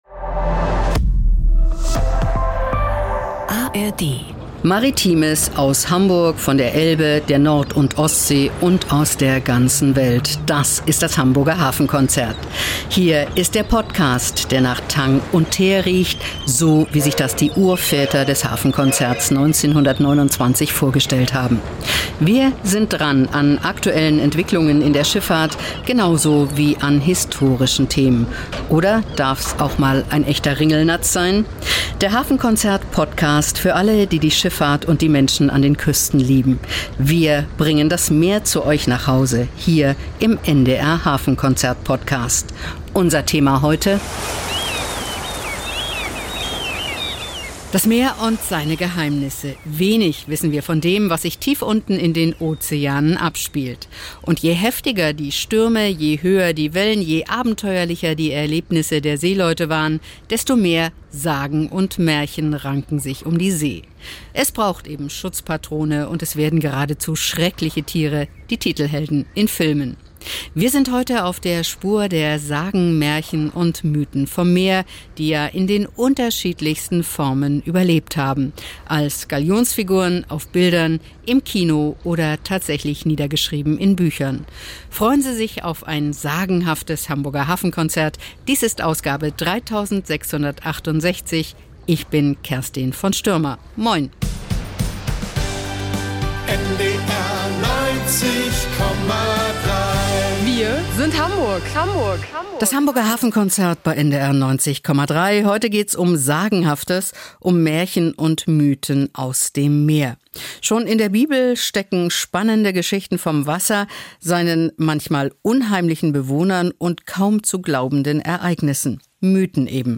Spannende Reportagen und exklusive Berichte rund um den Hamburger Hafen, die Schifffahrt und die norddeutsche Geschichte.